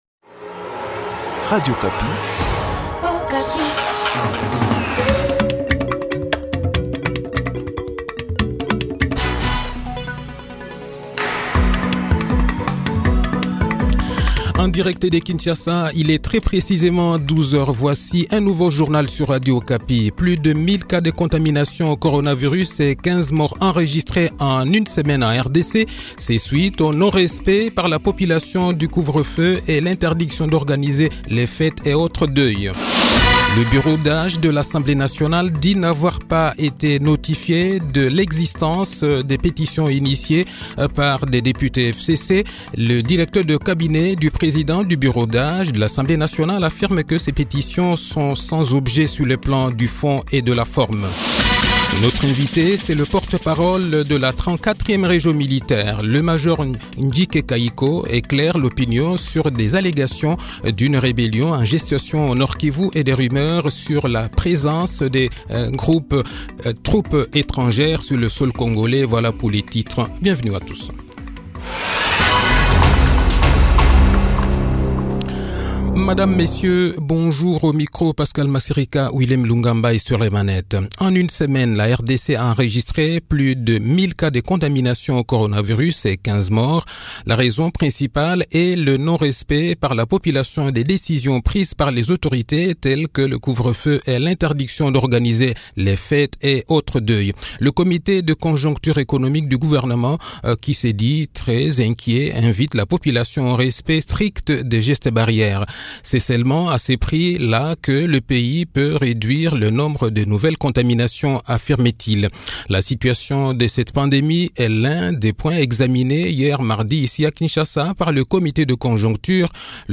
Le journal-Français-Midi
Le journal de 12 h, 13 Janvier 2021